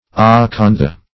Acantha \A*can"tha\, n. [Gr.